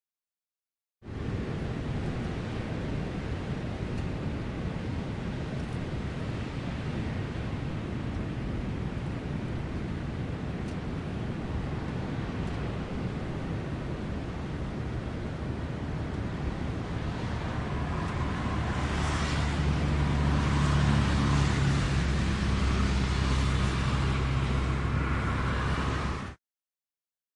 大自然 " 海浪 遥远的光影交通
Tag: 场记录 沙滩 海岸 海浪 交通 海岸 海洋 海滨 海岸 冲浪